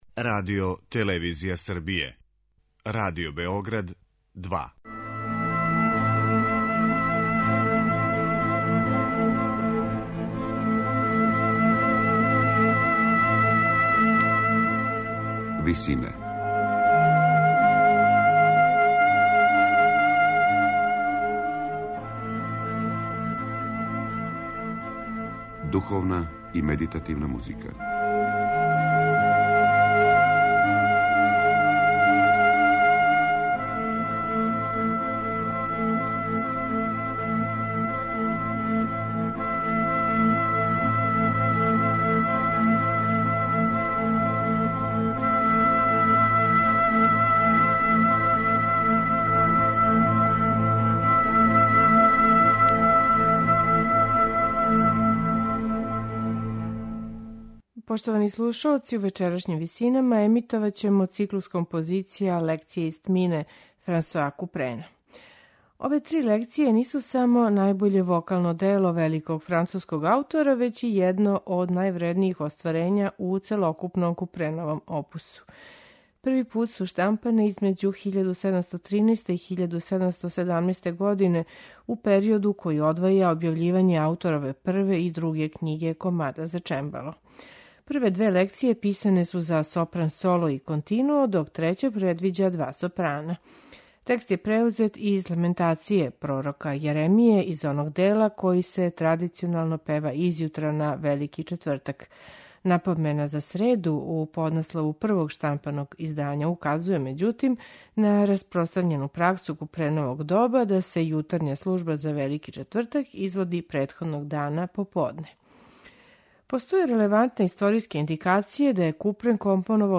виола да гамба
чембало и оргуље